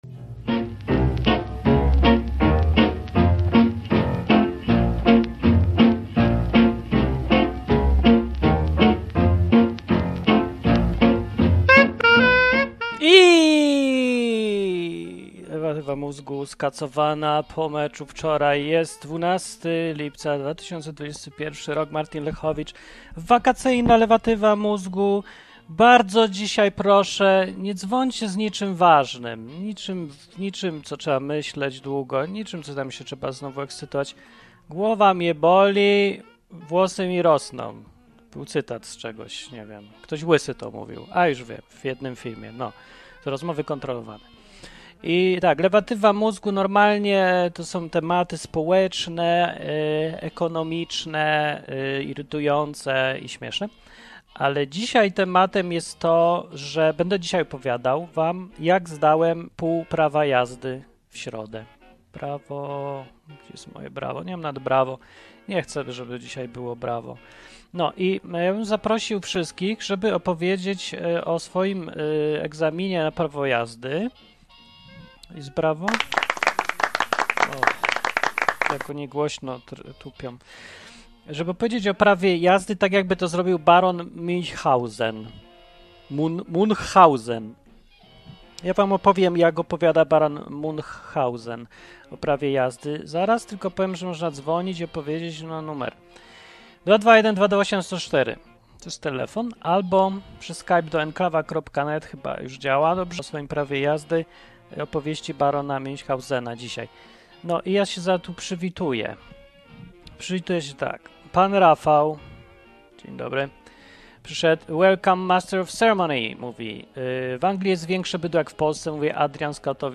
Program satyryczny, rozrywkowy i edukacyjny.